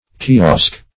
Kiosk \Ki*osk"\, n. [Turk. kiushk, ki["o]shk, Per. k?shk.]
kiosk.mp3